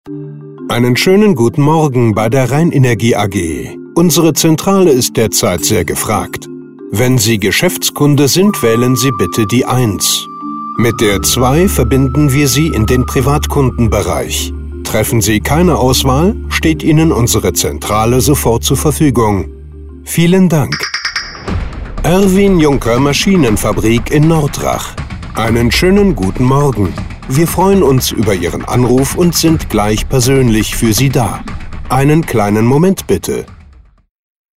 Anrufbeantworter-Ansage Sprecher - Synchronsprecher
🟢 Standard Sprecher